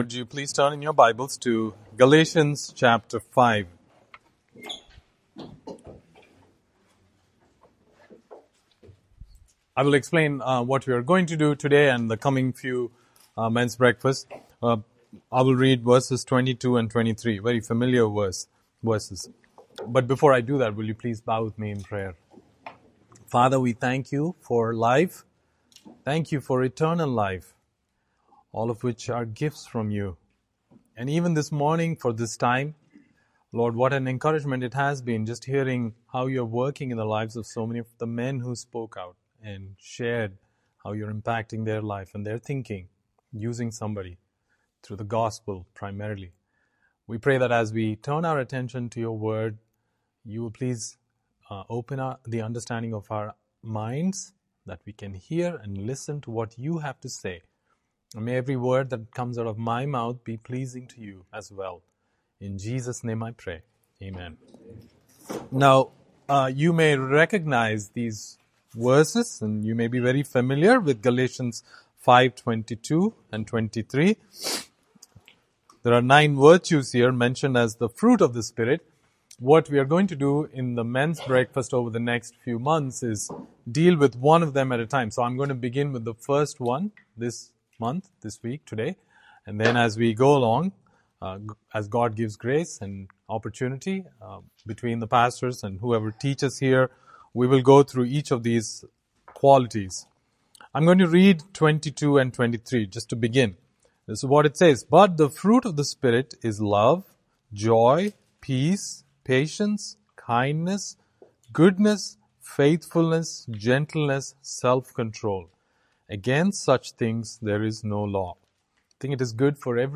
Men's Breakfast